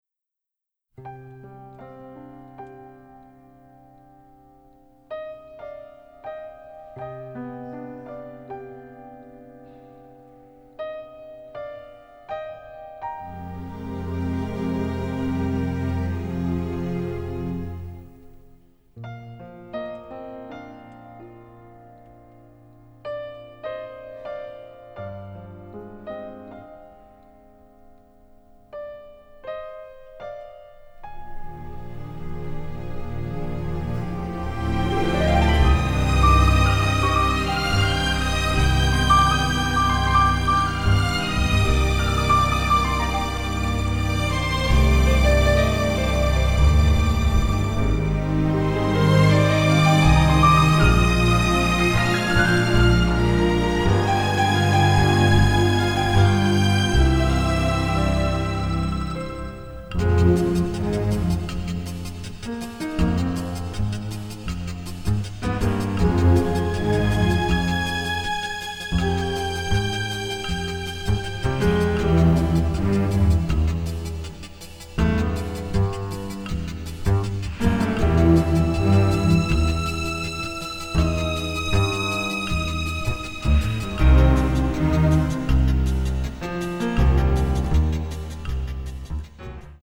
romantic noir score